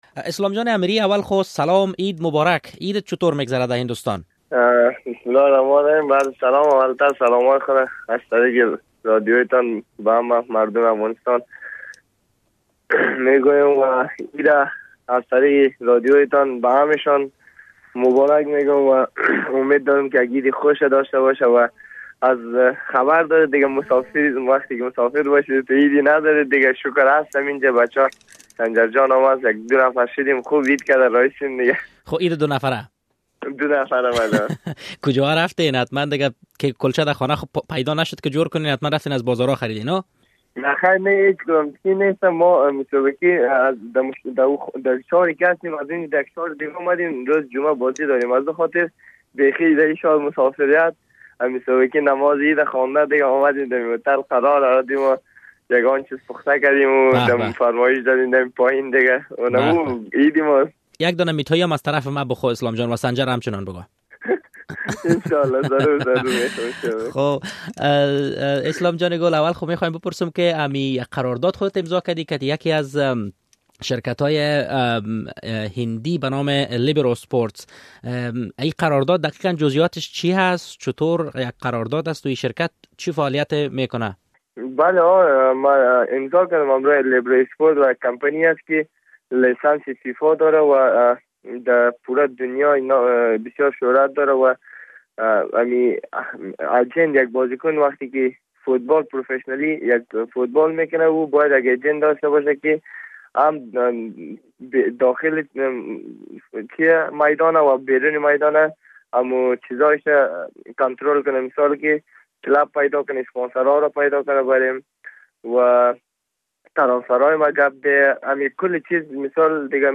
مصاحبه با اسلام امیری سرتیم فوتبال افغانستان